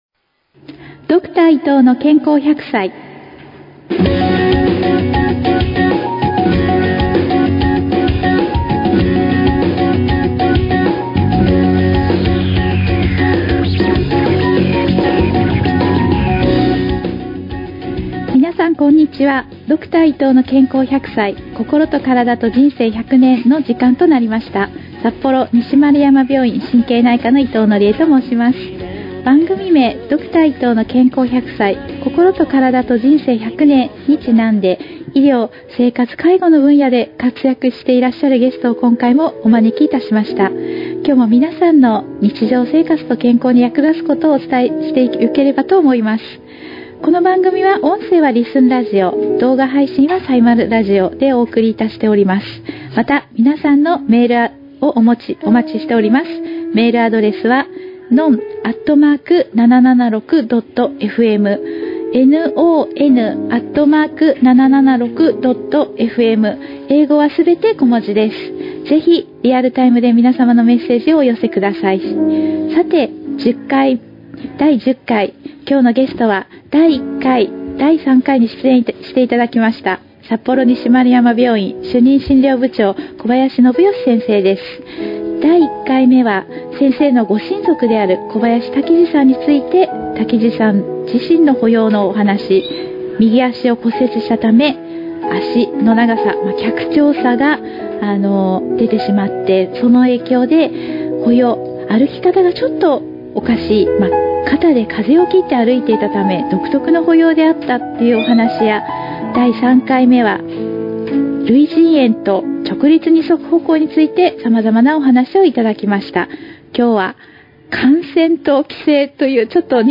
ラジオ放送で流した楽曲は２次利用できないので、曲を外したものです。
： ファイルを小さくするためビットレートを下げたので元の音源より音質低下あり。